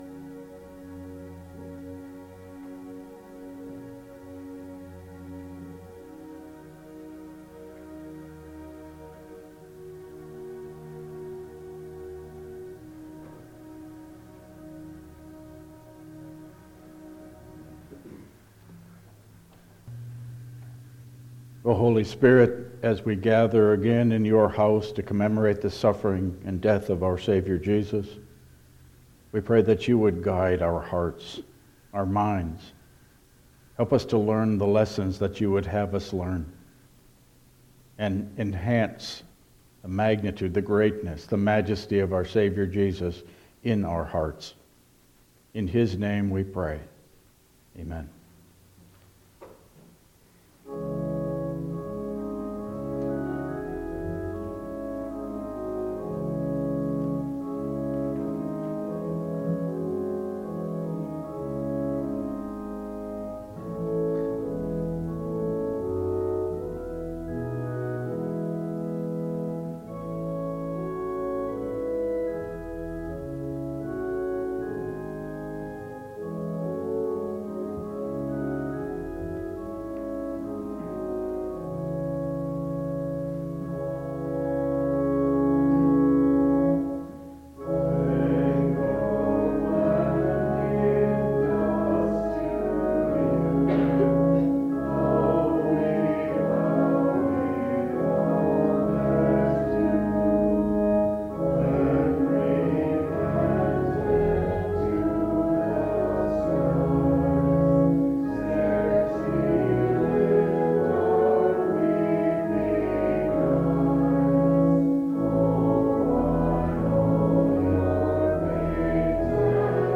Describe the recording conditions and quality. Passage: John 19:19-22 Service Type: Lenten Service